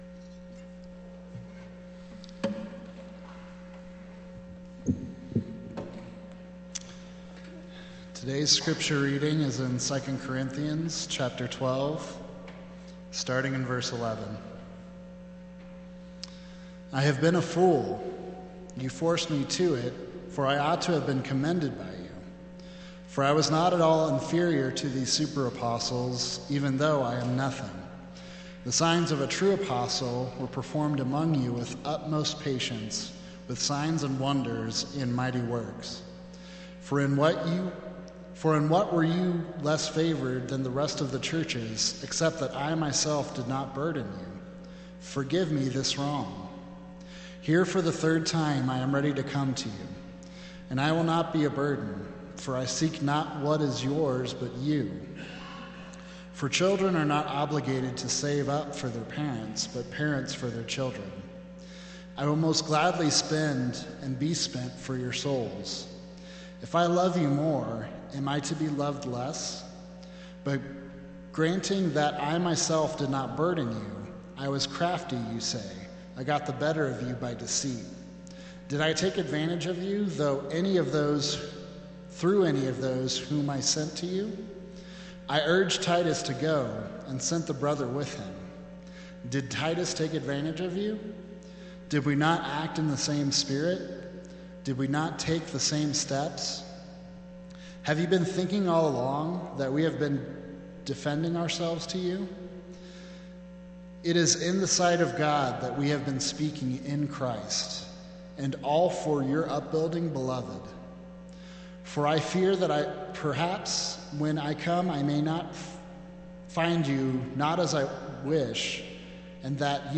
Sermon Archives | Aspen Ridge Church